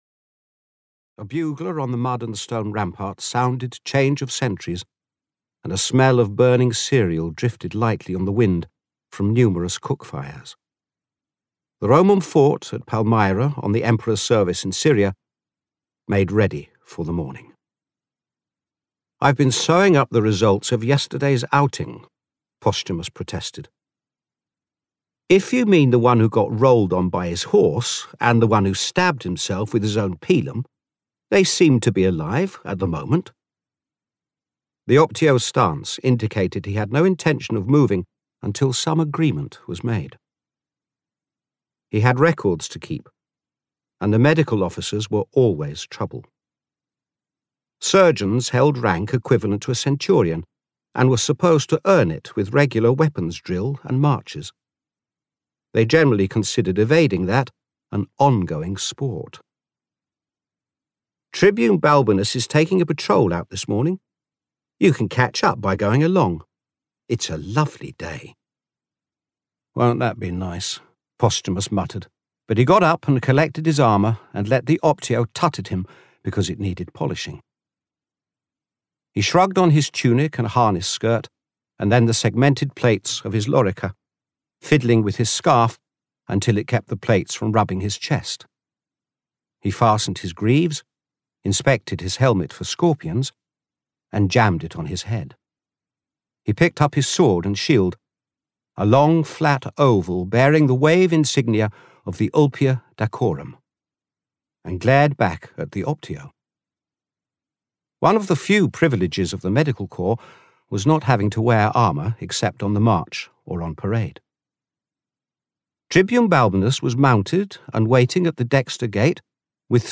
Audiobook The Wall at the Edge of the World, written by Damion Hunter.
Ukázka z knihy